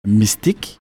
Mystic play all stop prononciation Mystic ↘ explication Mystic, on prononce ça quand … quelque chose de grave, tu dis “Mystic”.